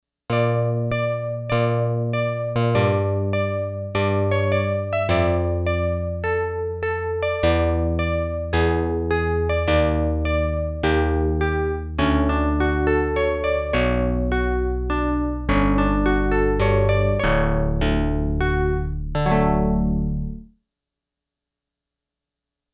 PPG Wave 2.2 audio demos
Polyphony: 8-voice